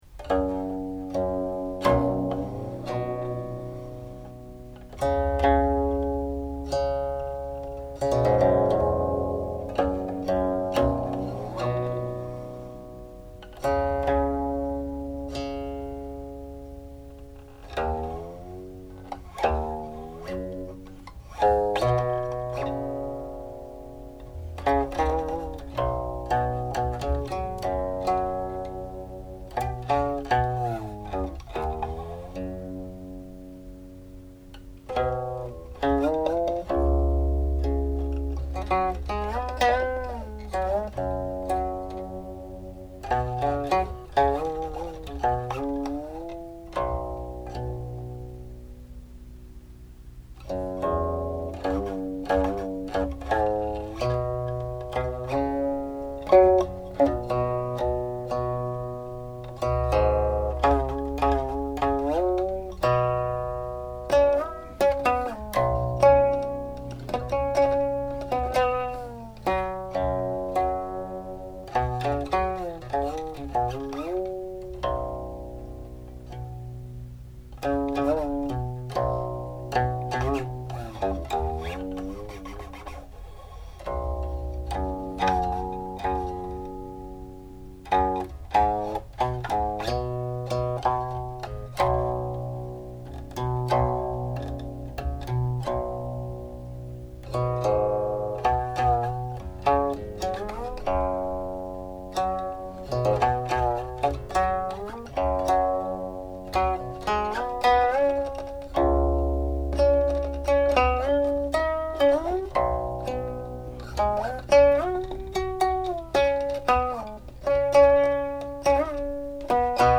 Seven Sections, untitled22 (see transcription; timings follow
04.21       harmonic coda
This 1579 version is the only shuixian melody I actively play.